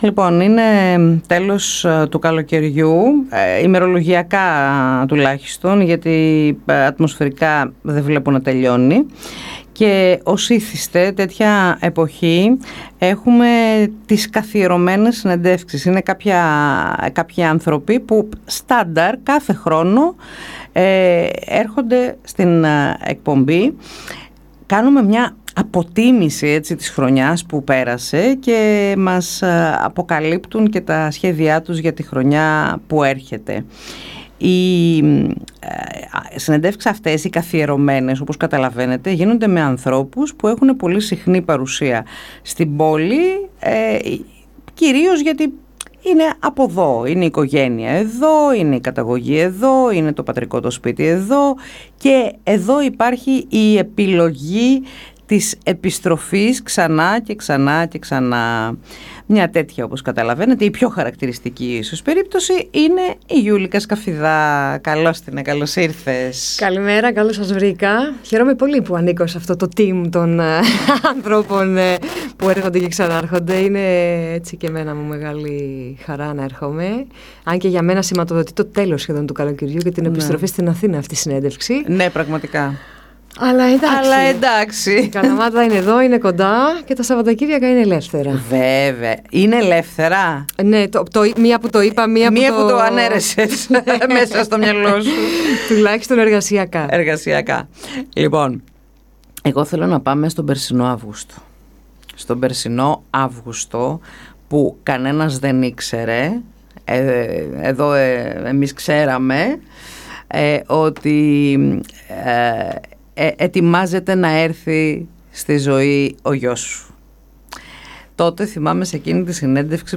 Όπως το συνηθίζει και τον φετινό Αύγουστο, έδωσε συνέντευξη στην ΕΡΤ Καλαμάτας, μιλώντας στην εκπομπή «12 – 1… Ψυχραιμία»